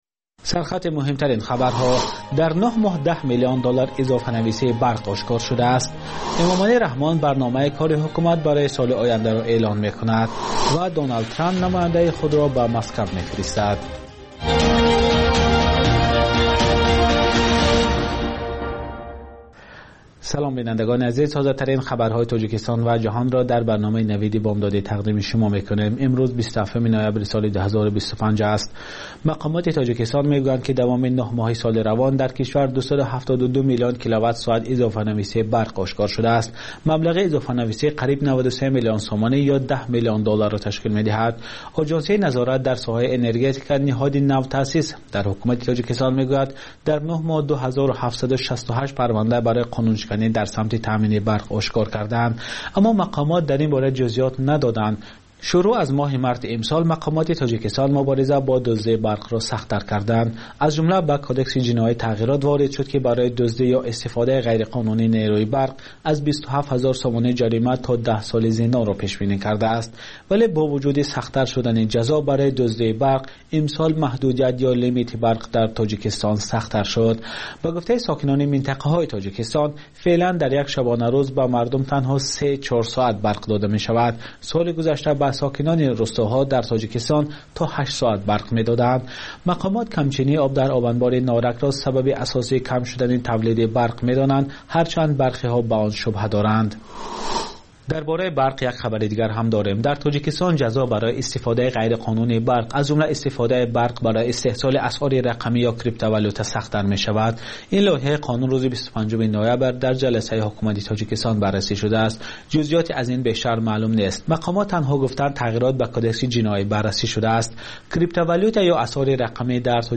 Пахши зинда
Маҷаллаи хабарӣ